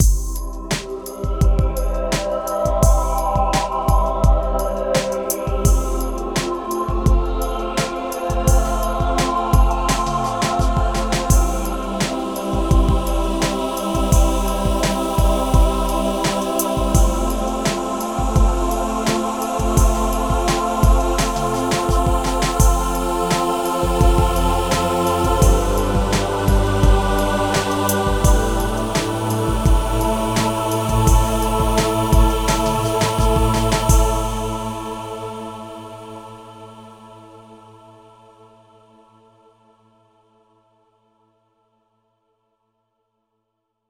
Here's 4 free program sounds powered by choir samples.
free-choir4.mp3